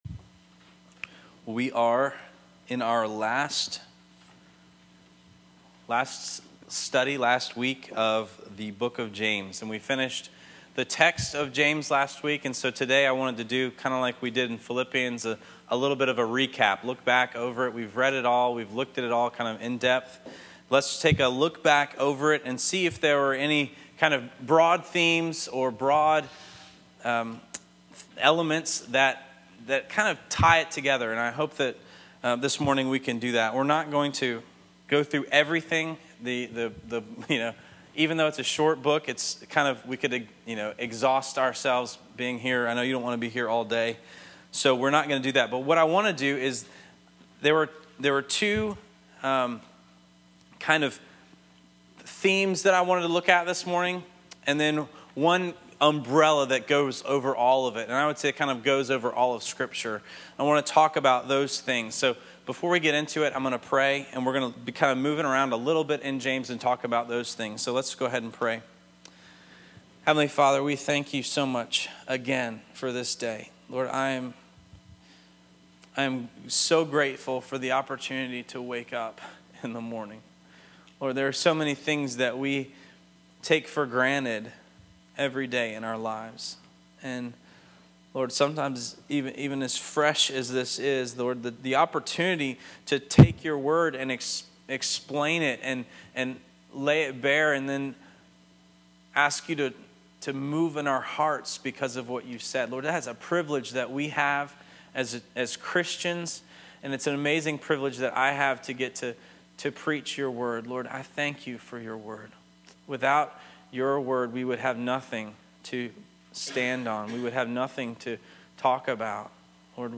Make sure you stream or download the sermon to get more than the notes offer.) james recap (link to audio file for…